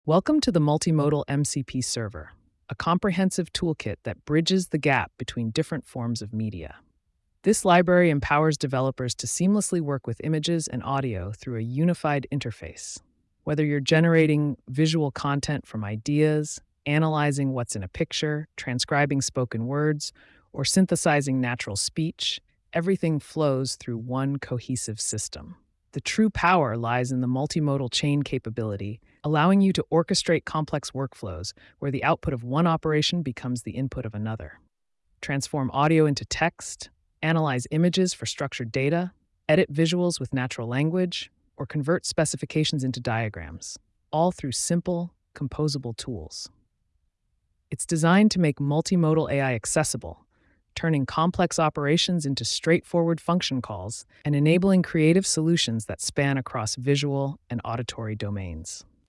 Audio description of the project
the audio file was created by the MCP server